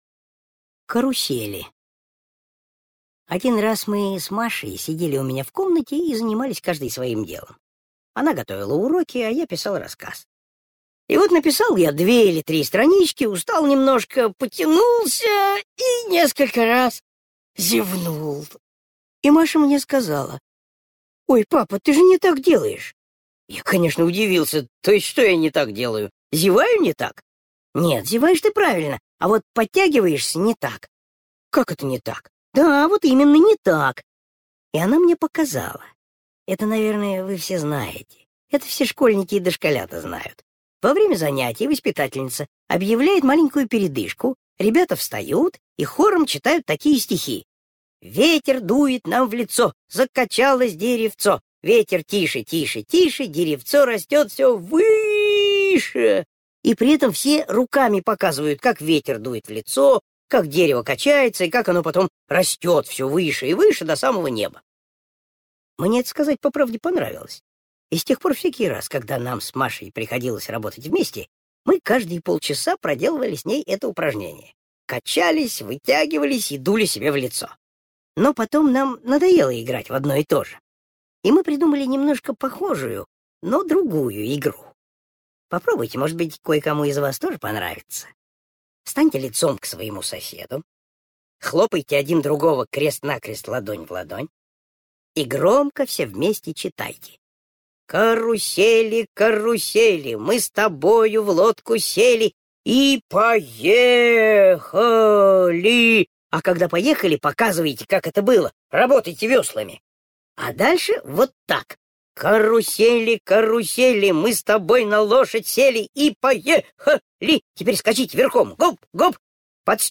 Аудиорассказ «Карусели»